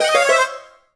slug_bad_kill_01.wav